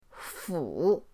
fu3.mp3